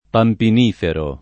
pampinifero [ pampin & fero ]